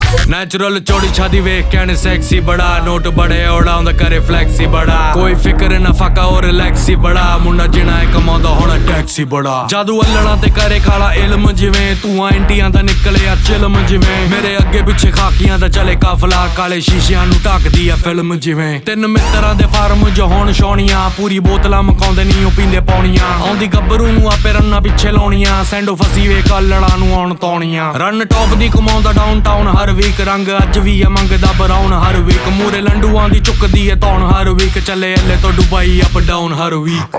Party Song